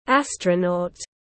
Astronaut /ˈæstrənɔːt/